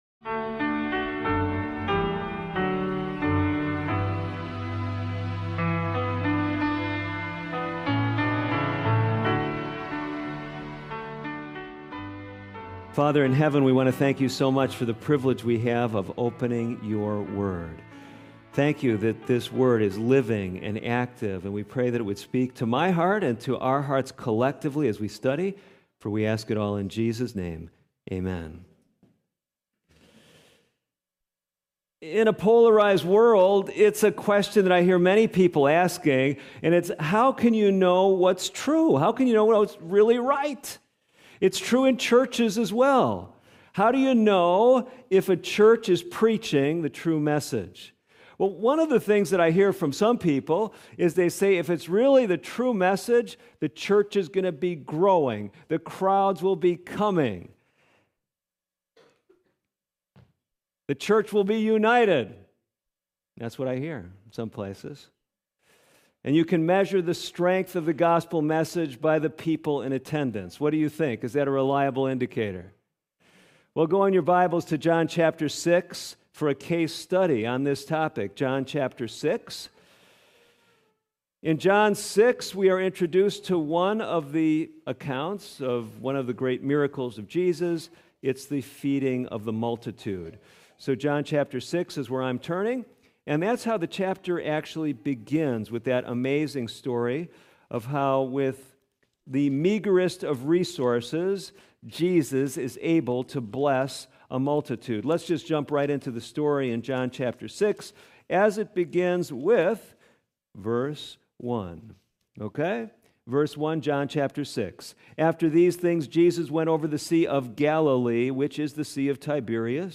This sermon explores the tension between appearance and truth, revealing how Scripture, faith, and personal failures all play a role in shaping a genuine relationship with Jesus. From miracles to Peter’s restoration, it’s a powerful reminder that grace meets us in our weakness and truth sustains us in uncertainty.